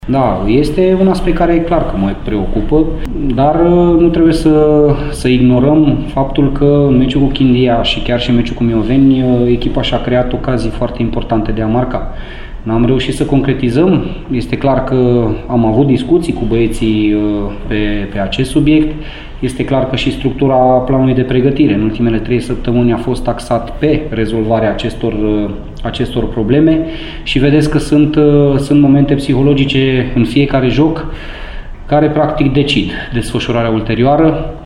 La conferința de presă premergătoare duelului cu argeșenii